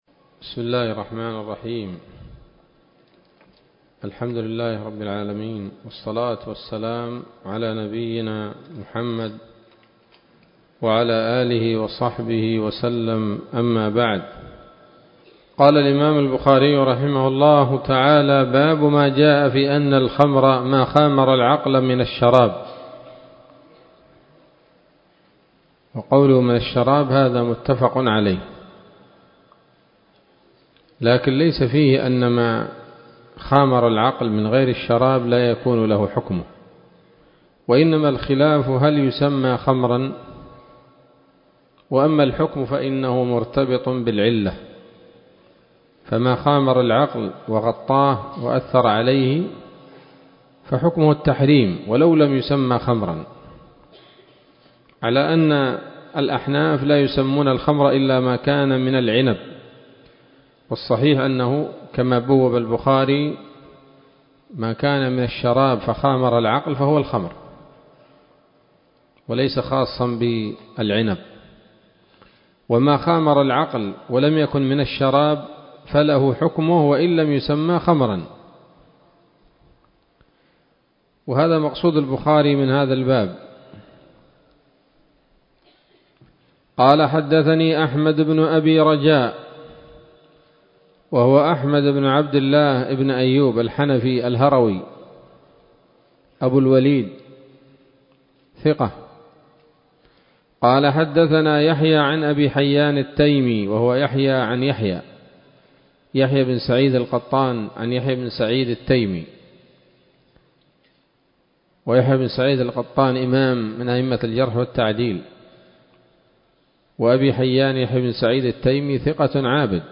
الدرس الخامس من كتاب الأشربة من صحيح الإمام البخاري